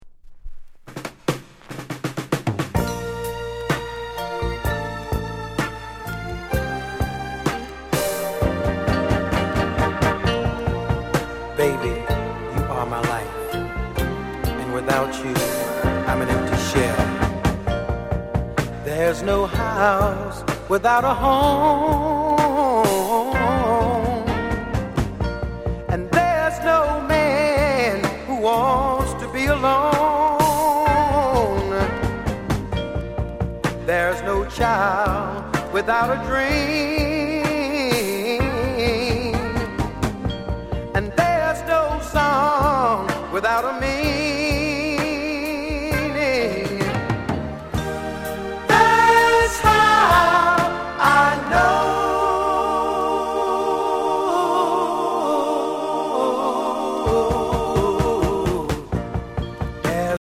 JAMAICAN SOUL